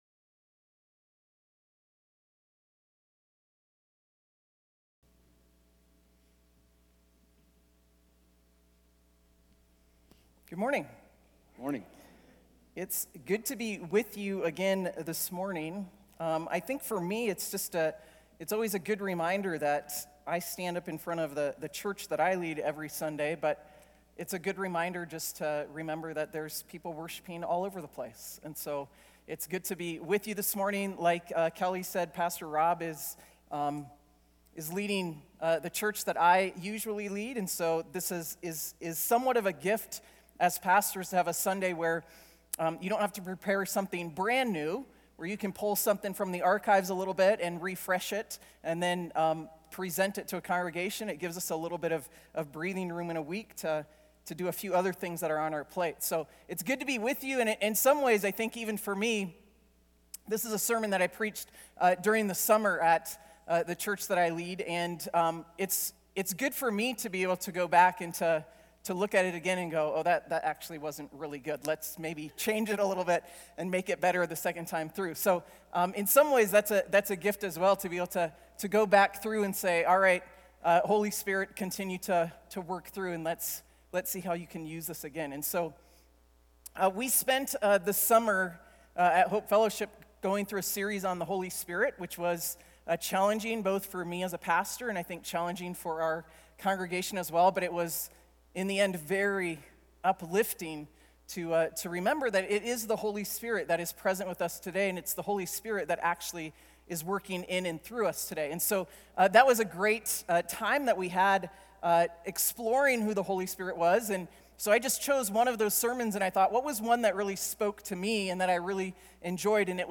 A message from the series "Guest Speaker."